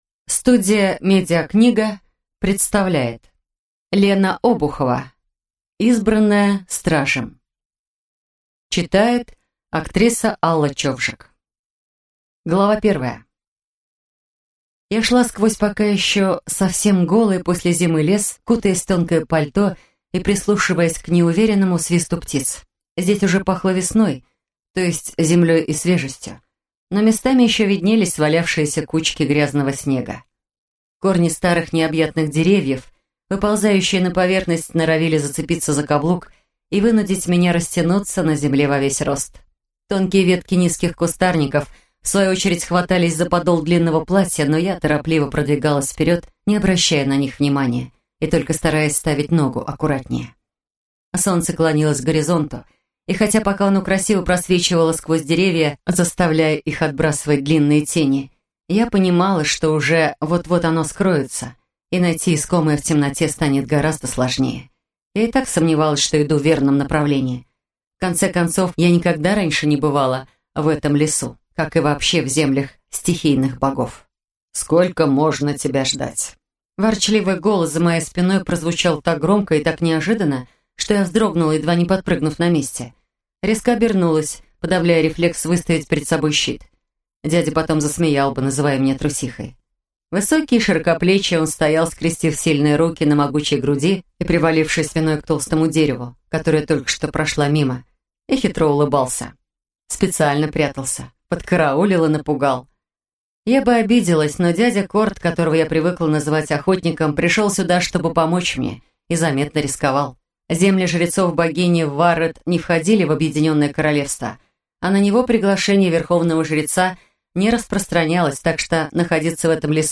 Аудиокнига Избранная стражем | Библиотека аудиокниг